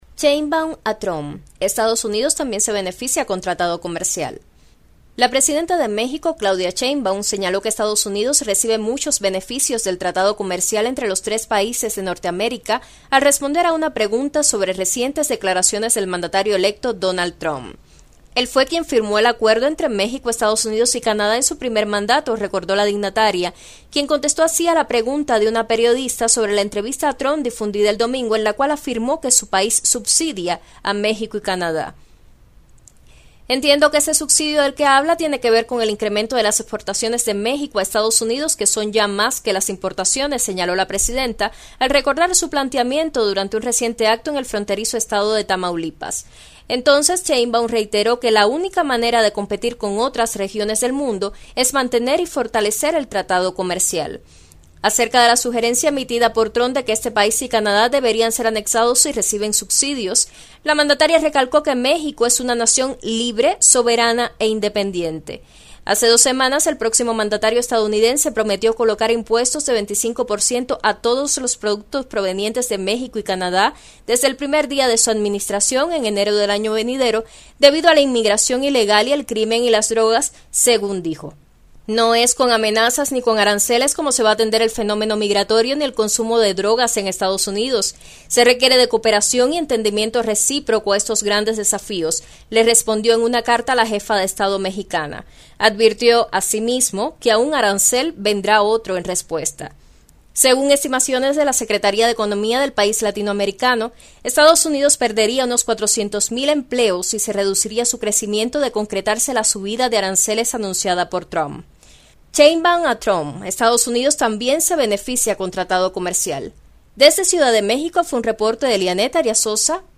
desde Ciudad de México